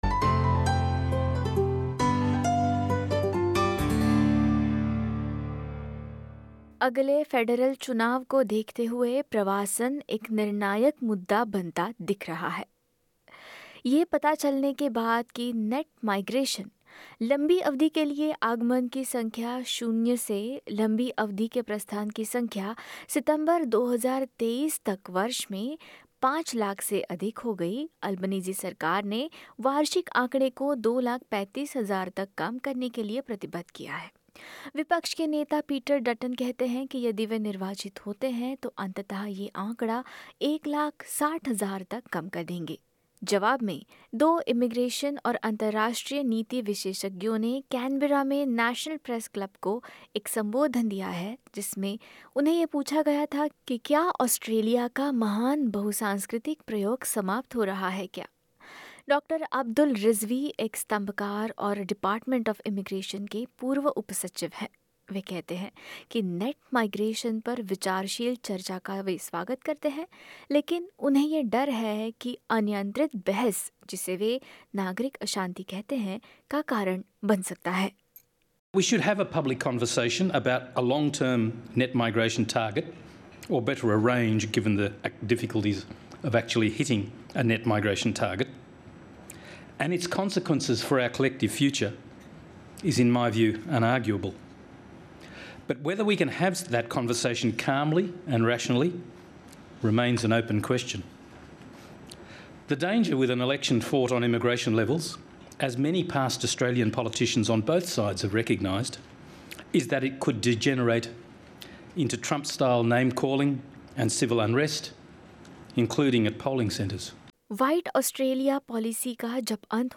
कैनबरा में नेशनल प्रेस क्लब को संबोधित करते हुए माइग्रेशन और अंतरराष्ट्रीय संबंधों के दो विशेषज्ञों ने यही सवाल उठाया है। जैसा कि एक रिपोर्ट बताती है, उन्होंने नेट माइग्रेशन और अंतरराष्ट्रीय छात्रों की भूमिका की एक नई समझ का आह्वान किया है।